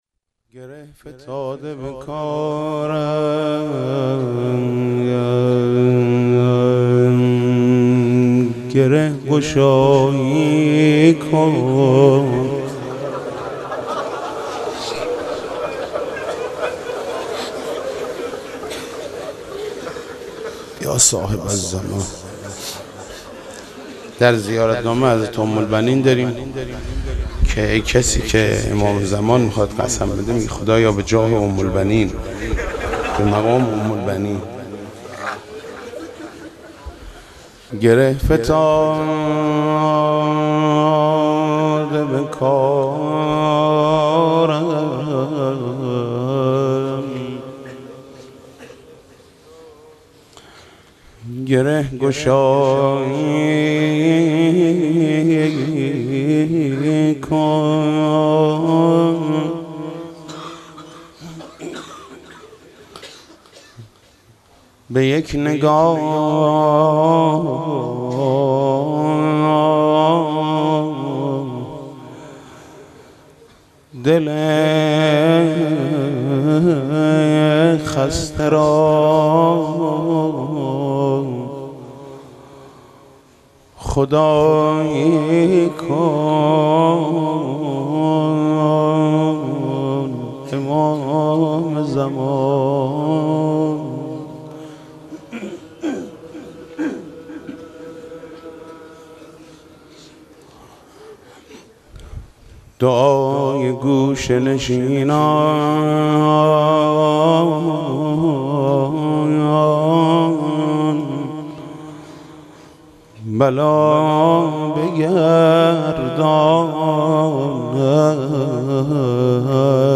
مناسبت : وفات حضرت ام‌البنین سلام‌الله‌علیها
مداح : محمود کریمی قالب : روضه